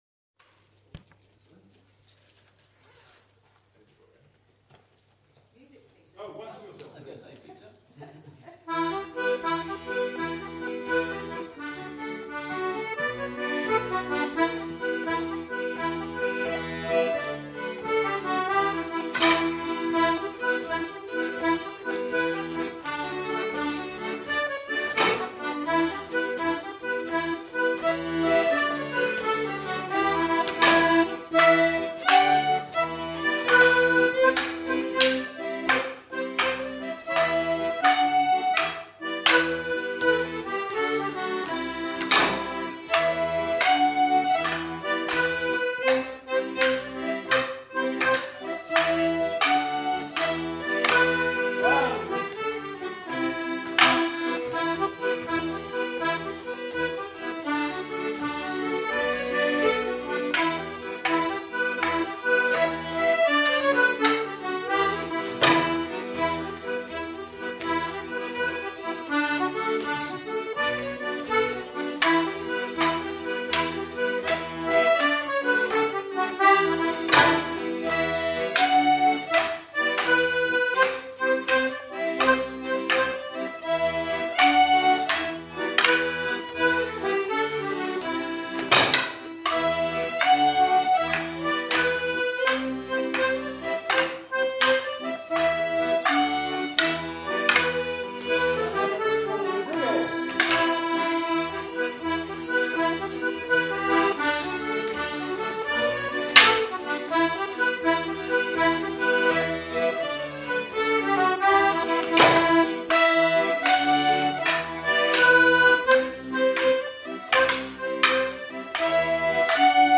• Melodeons (D/G)
• Piano accordions
• Concertinas
• Fiddles
At the beginning of 2019 we embarked on a project of recording our practices so that the tunes can be used for personal music practice purposes. These recordings are of the whole dance (including the 'Once To Yourself', but not the walk on or walk off).